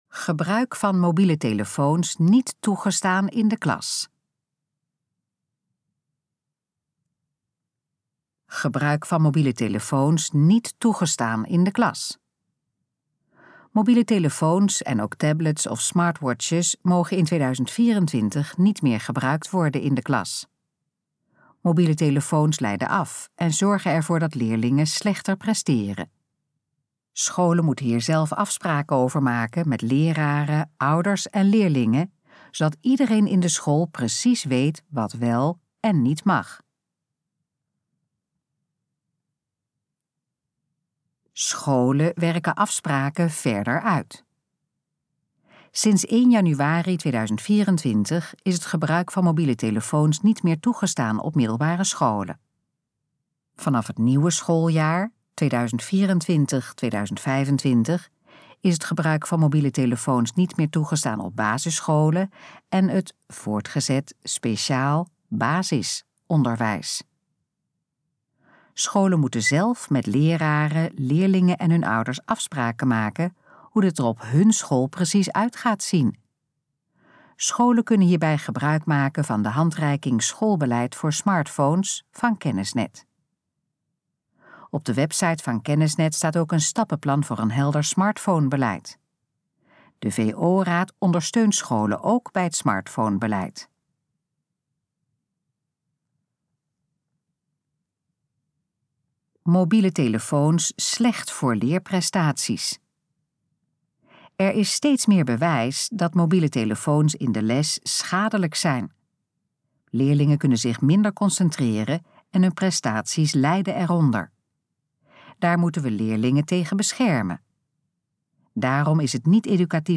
Dit geluidsfragment is de gesproken versie van de pagina: Gebruik van mobiele telefoons niet toegestaan in de klas.